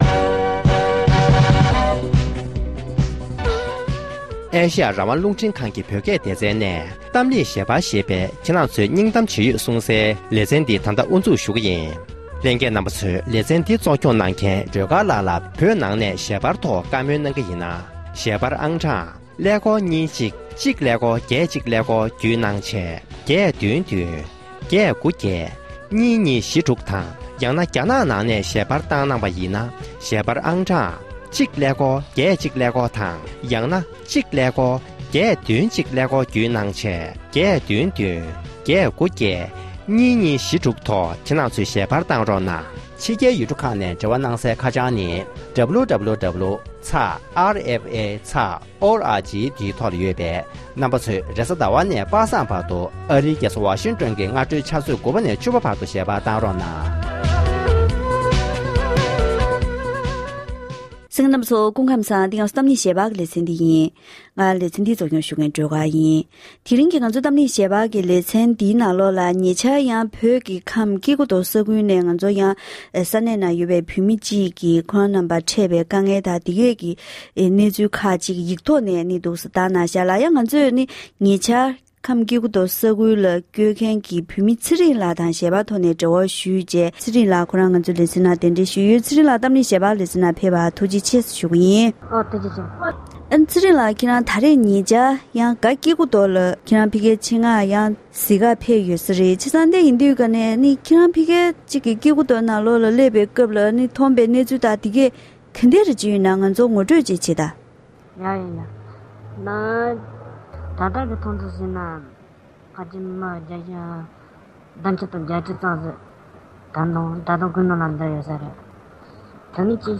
༄༅༎དེ་རིང་གི་གཏམ་གླེང་ཞལ་པར་གྱི་ལེ་ཚན་ནང་།